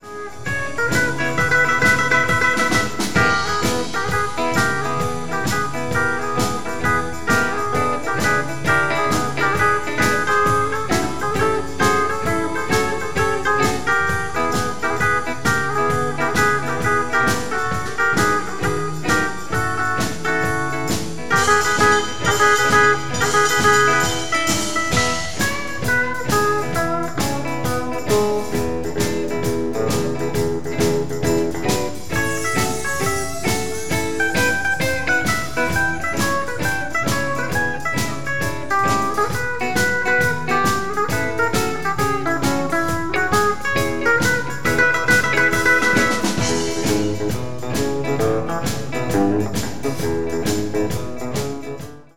Konzert 2009 -Download-Bereich